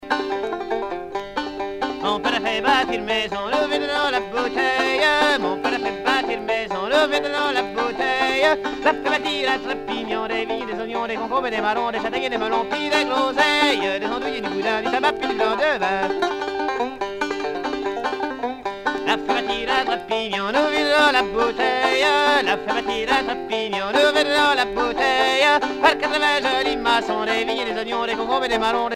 Genre laisse
Chants à répondre et à danser
Pièce musicale éditée